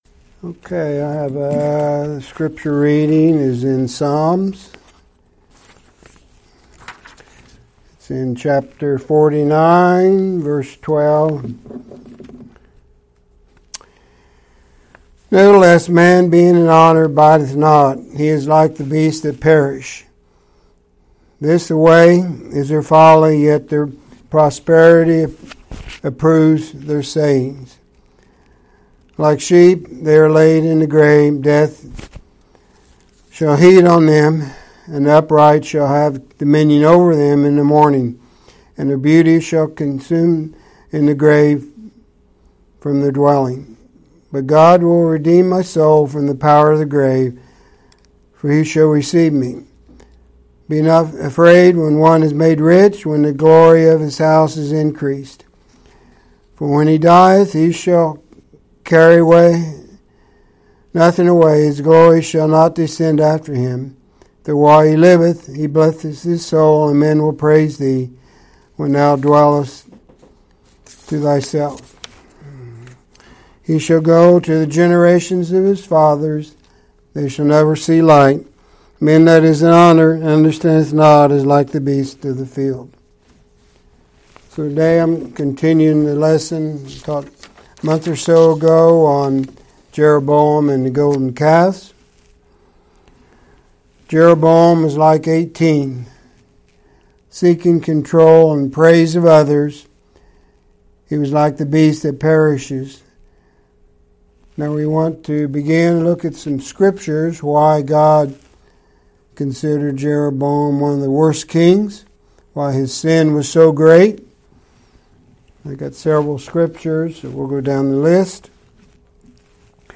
2023 Sermons